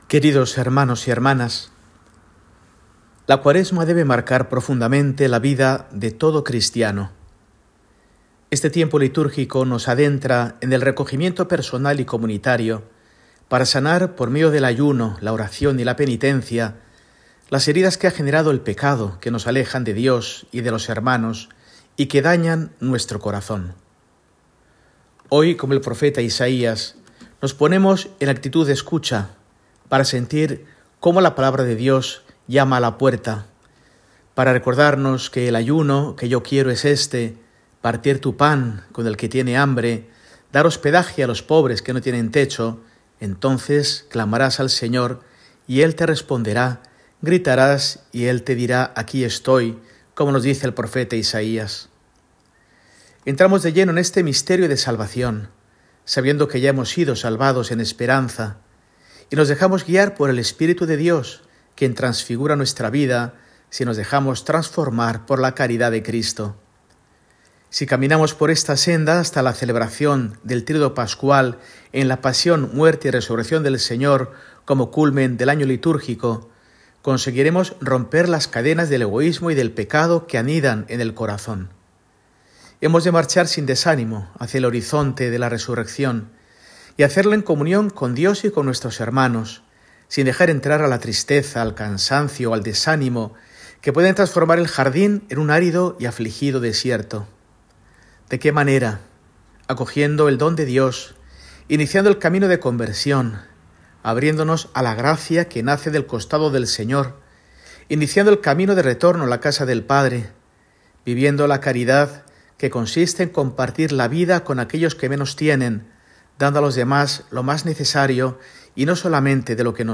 Mensaje semanal de Mons. Mario Iceta Gavicagogeascoa, arzobispo de Burgos, para el domingo, 9 de marzo de 2025, I de Cuaresma